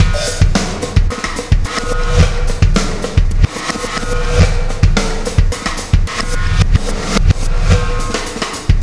Black Hole Beat 10.wav